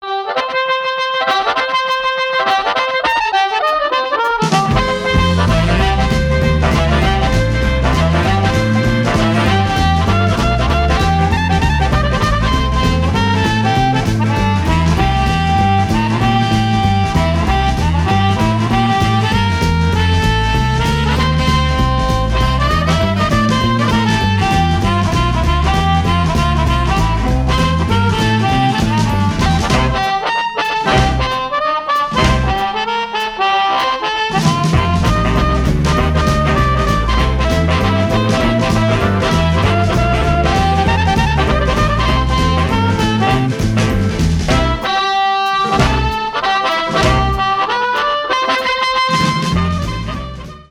Chicago influenced harp playing